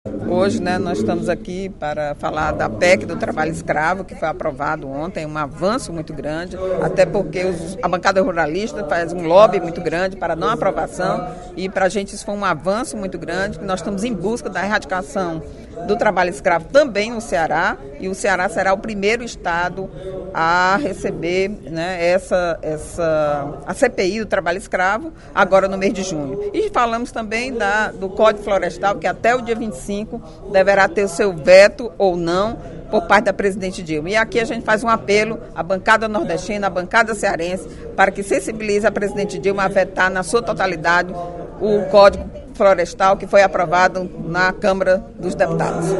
A deputada Eliane Novais (PSB) comemorou nesta quarta-feira (23/05), em pronunciamento na Assembleia Legislativa, a aprovação ontem, na Câmara dos Deputados, da proposta de emenda à constituição (PEC) do Trabalho Escravo.